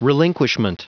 Prononciation du mot relinquishment en anglais (fichier audio)
Prononciation du mot : relinquishment